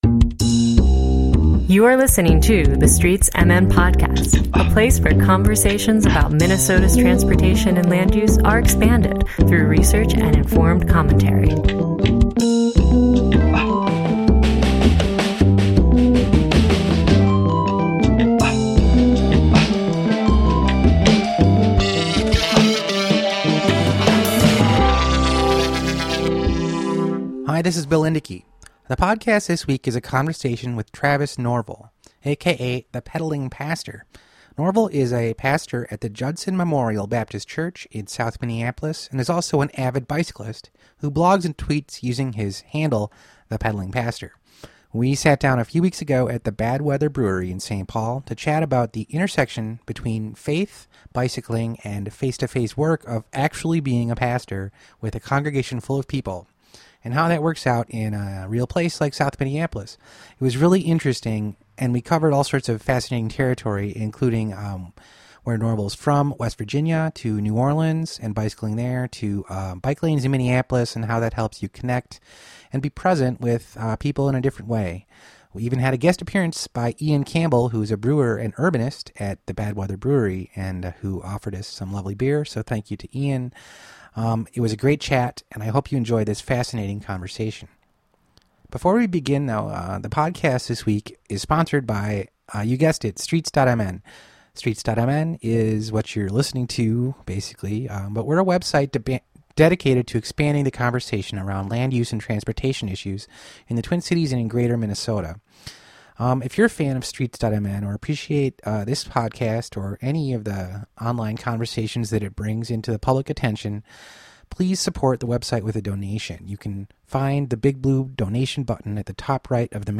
I hope you enjoy this fascinating conversation.